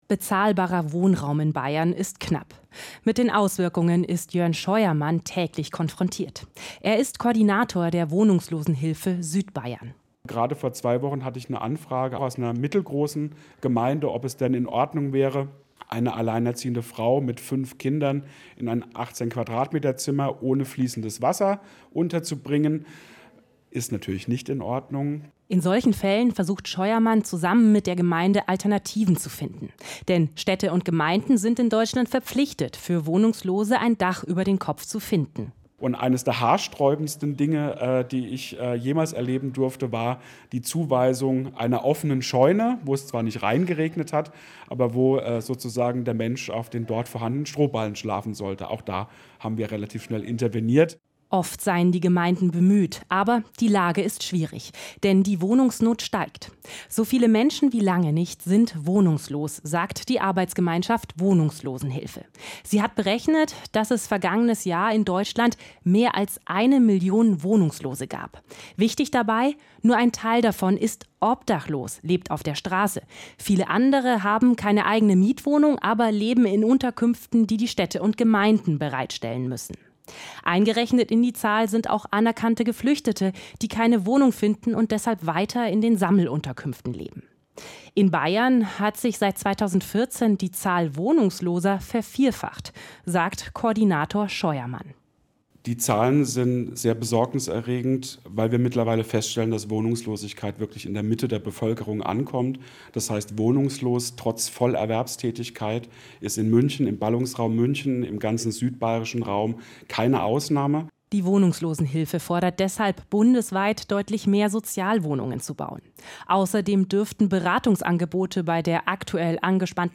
Kommentar der Koordination Wohnungslosenhilfe Südbayern in Bayern2: